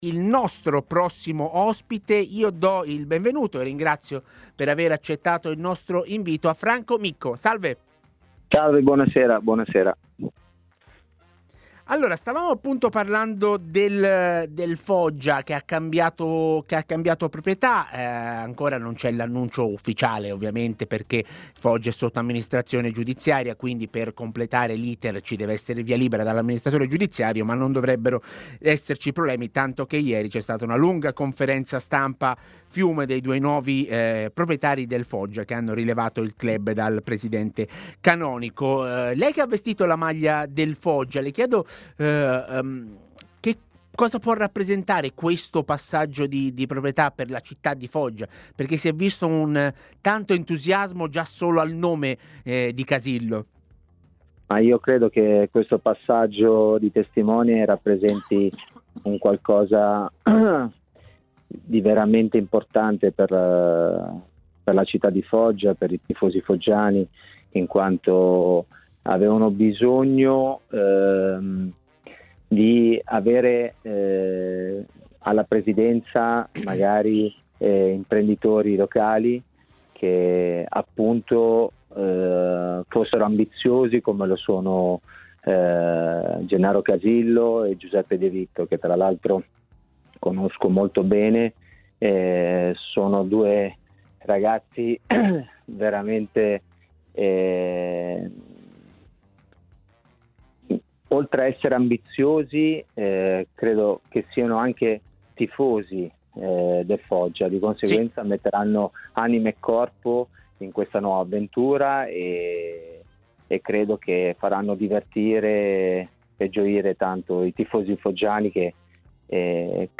ex difensore del Foggia, è intervenuto come ospite di 'A Tutta C', trasmissione in onda su TMW Radio e iL61 per commentare l'imminente passaggio di società del club rossonero.